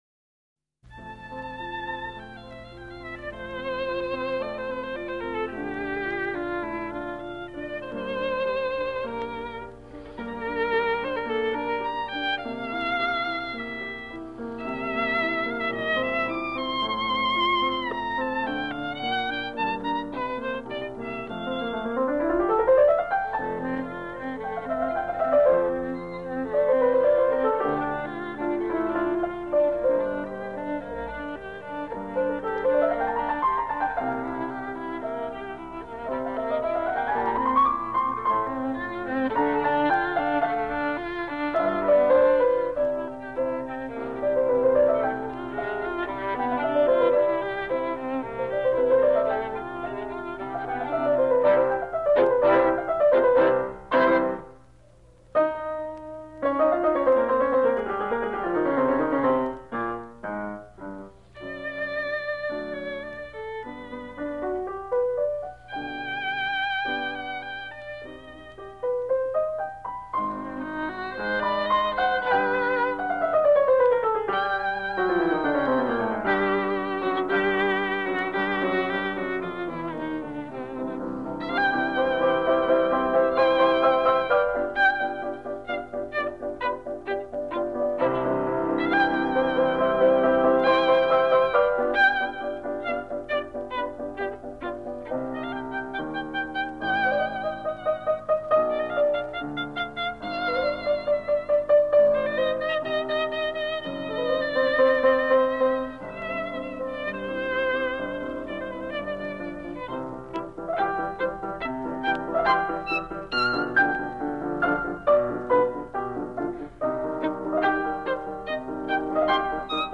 鋼琴
音樂類型：古典音樂
收錄自1944年在美國國會圖書館的三場演奏會實況
他們直率、平穩並且一致的音樂步伐，在自然流動的旋律中有著適度的緊張氣氛和音樂張力。